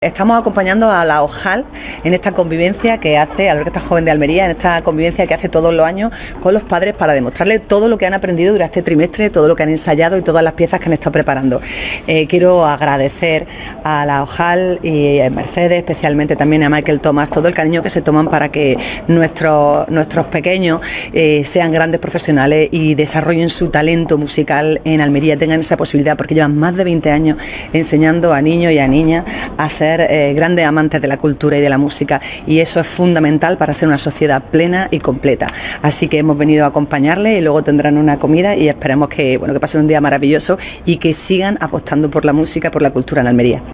María del Mar Vázquez les ha dirigido unas palabras, en las que ha afirmado que “quiero agradecer a los profesionales de la OCAL el cariño y conocimientos que aportan a la OIAL y a La OJAL para que se apasionen con la música clásica y crezcan como intérpretes.
TOTAL-ALCALDESA-CON-OIAL-Y-OJAL-EN-NAVIDAD.wav